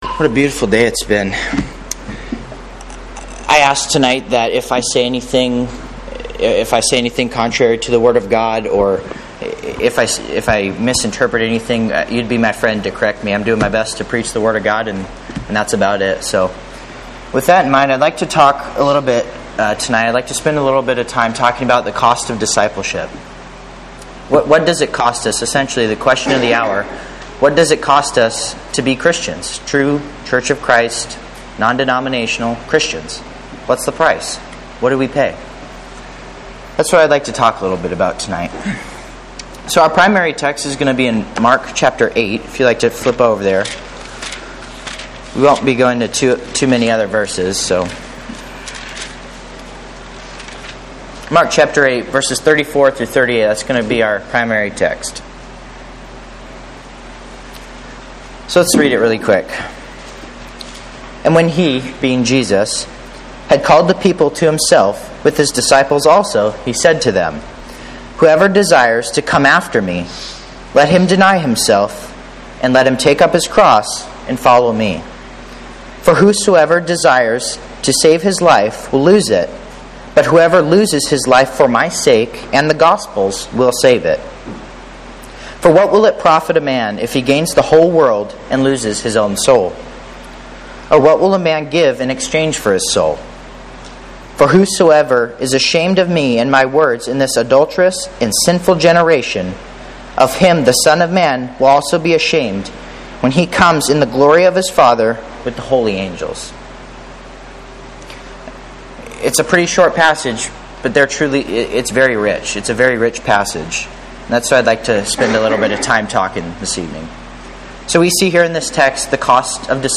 sermon, "Cost of Discipleship" from Sunday night